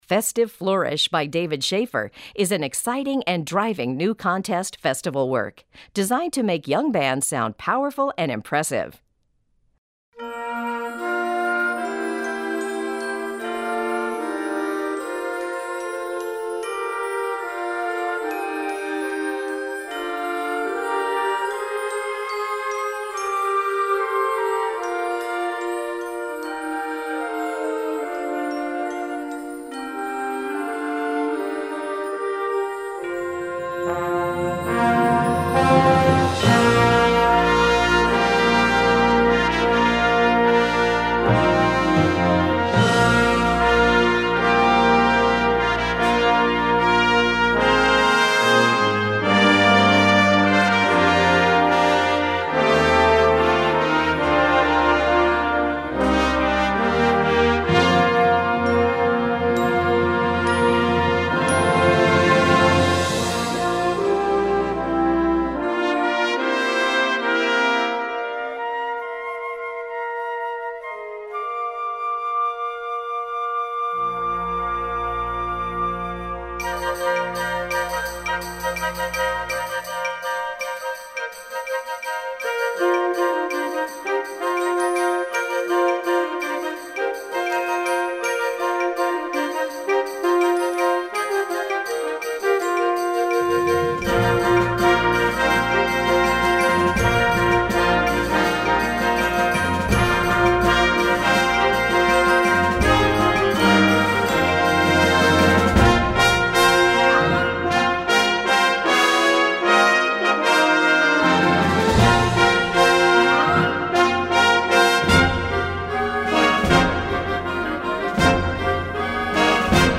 Catégorie Harmonie/Fanfare/Brass-band
Sous-catégorie Musique de concert